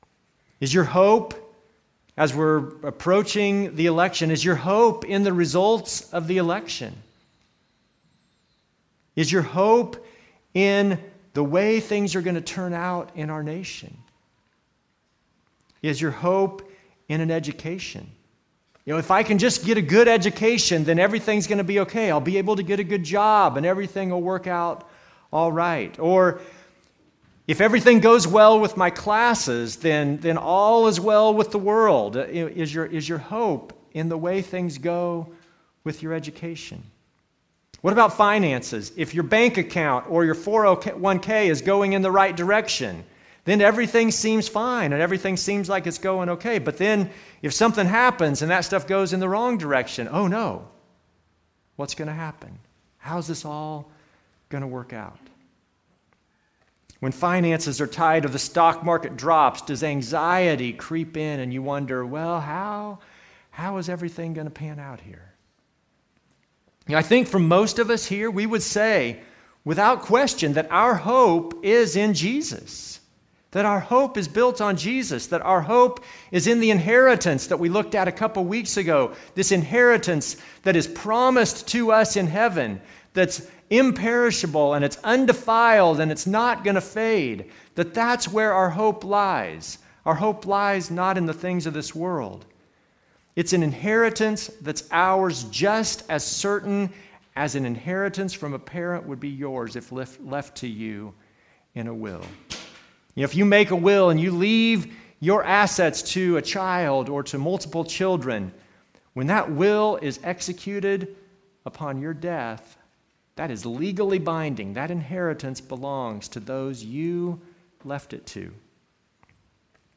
Passage: 1 Peter 1:13-21 Service Type: Normal service